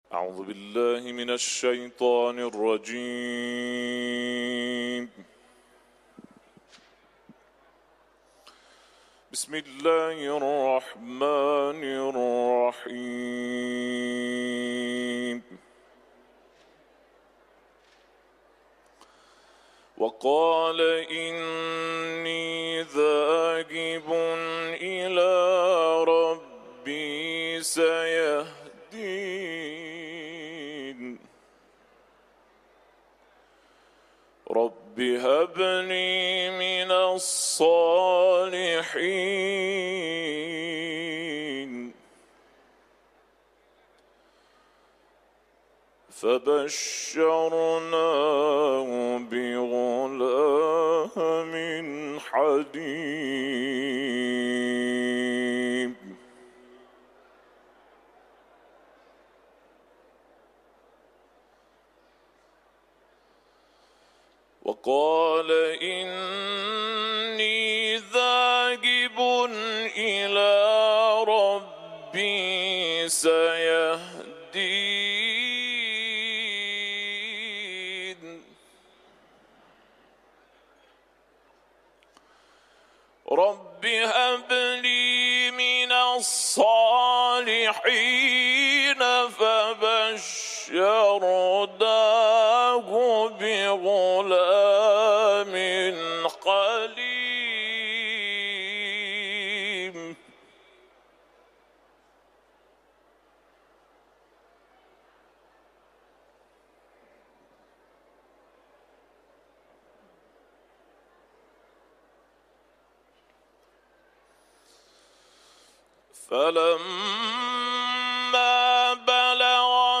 Etiketler: İranlı kâri ، Saffat suresi ، Kuran tilaveti